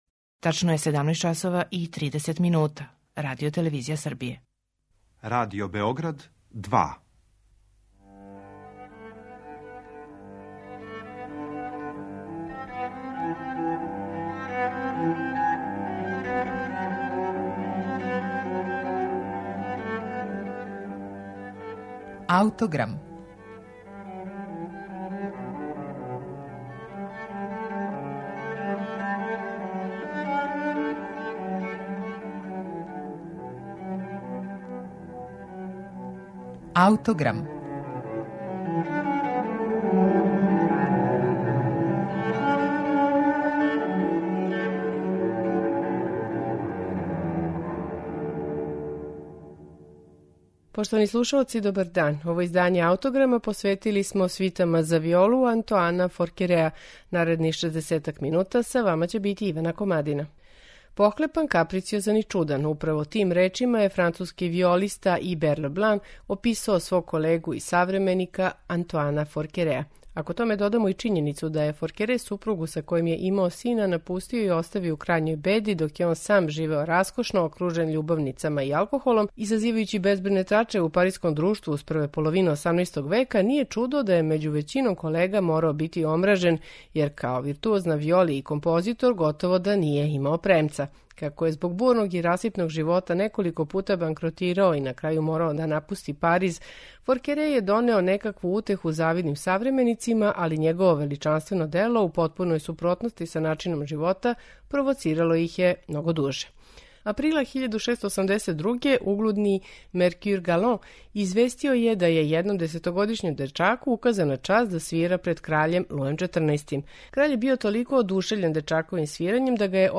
на оригиналним инструментима Форекереовог доба
виола да гамба
чембало
теорбе и барокне гитаре